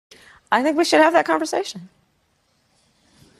Kamala Harris Conversation